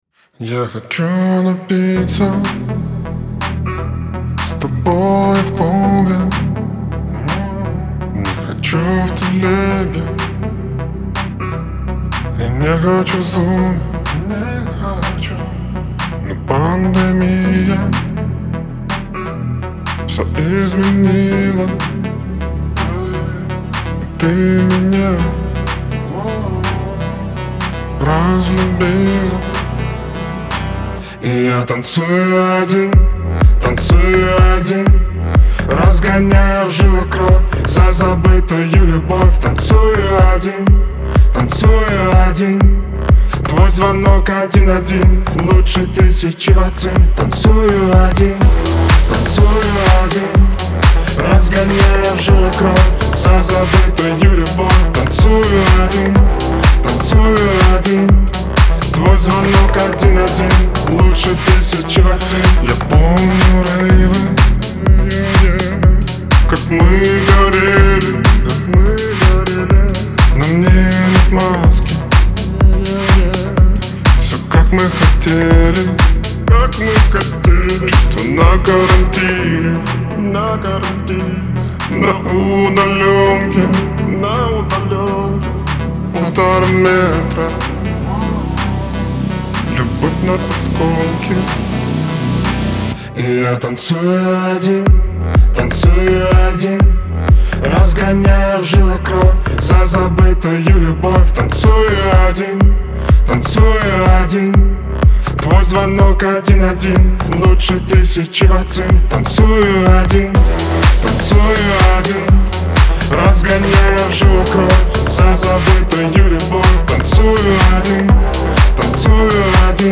динамичная и эмоциональная песня в жанре поп-электроники